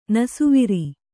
♪ nasuviri